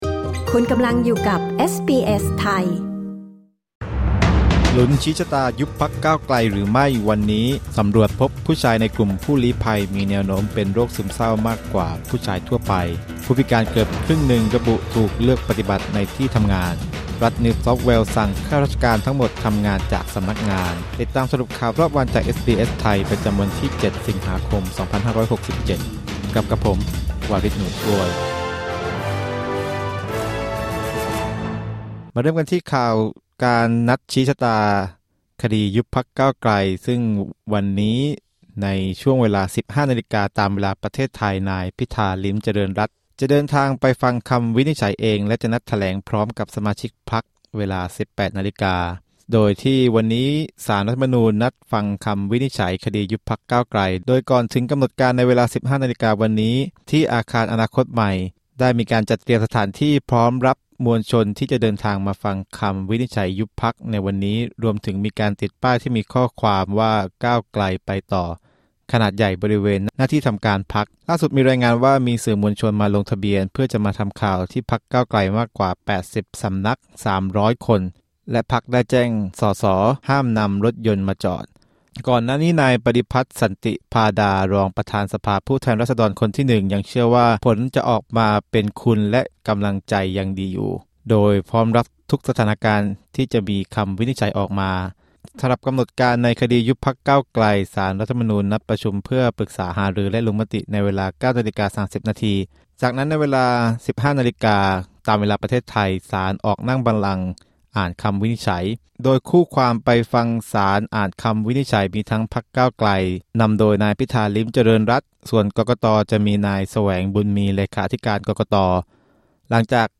สรุปข่าวรอบวัน 7 สิงหาคม 2567